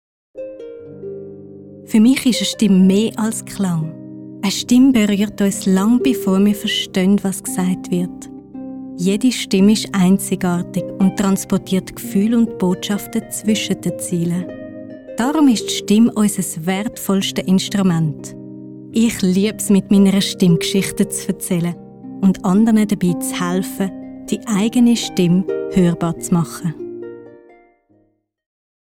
Werbekampagnen, Imagefilmen, Hörspielen und Hörbüchern verleiht eine professionelle Sprecherin Klarheit und Ausdruck.